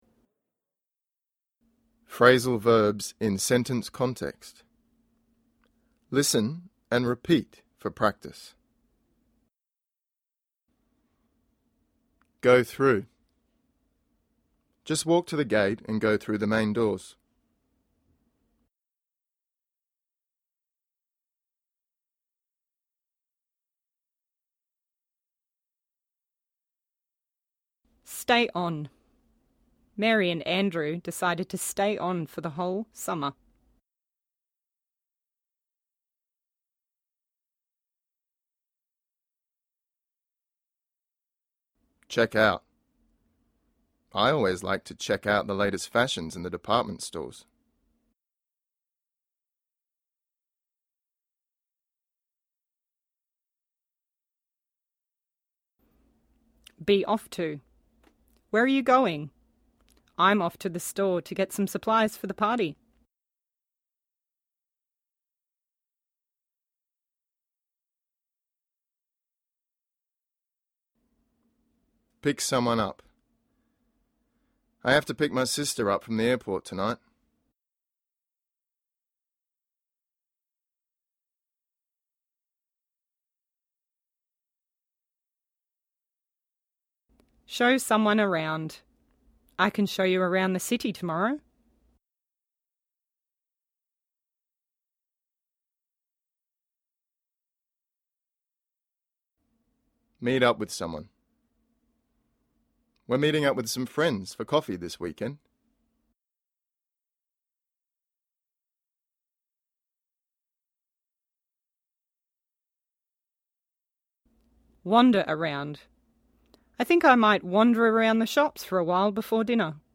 Try to copy the speech pattern as closely as possible.
Listen and Repeat